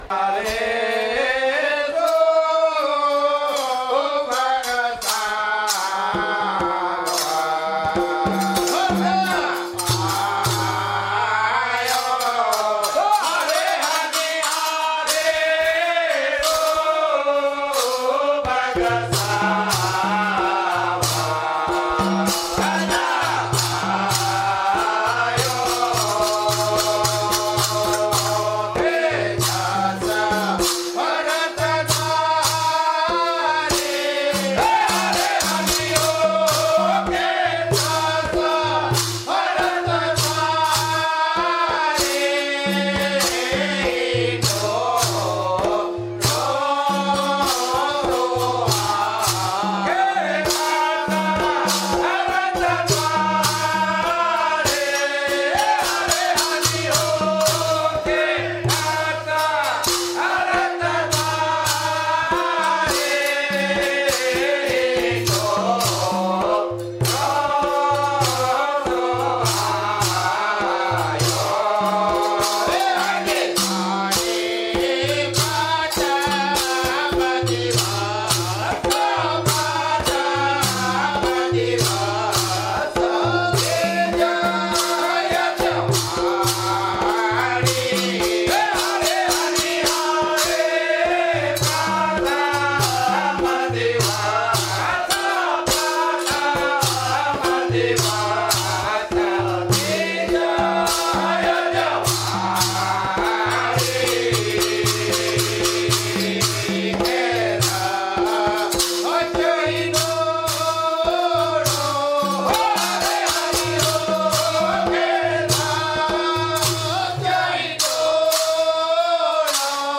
નાદબ્રહ્મ પદ - ૫૭૩, રાગ - હિંડોળાની સામેરી હનિહાંરે સુભગ સાવન ...